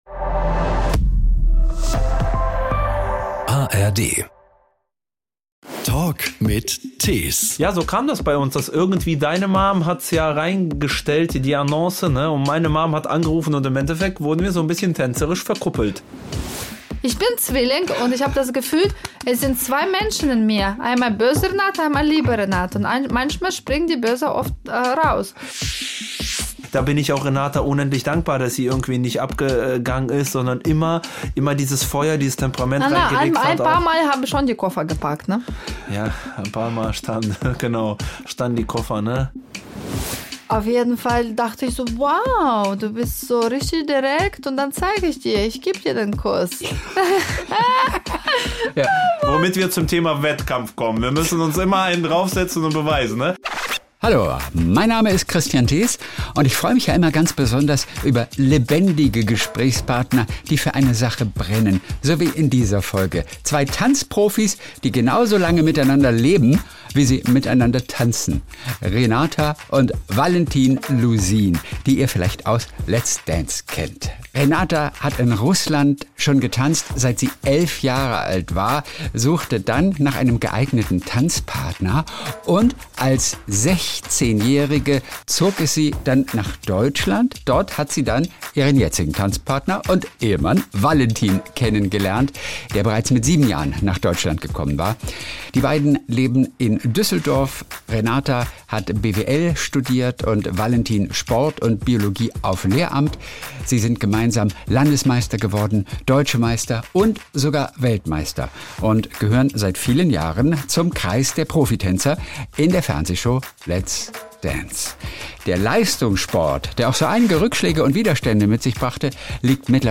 Hier erzählen sie ihre herrlich kuriose Geschichte. In diesem lebendigen Talk erfahrt ihr außerdem, dass sie anfangs überhaupt nicht zusammengepasst haben und dass da auch mal die Fetzen flogen.